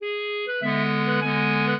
clarinet
minuet4-11.wav